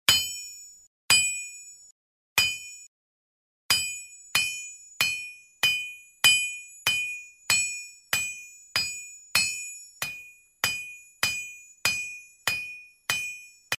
На этой странице собраны разнообразные звуки гвоздя: забивание, удары по металлу, скрип и другие эффекты.
Молотком стучат по металлу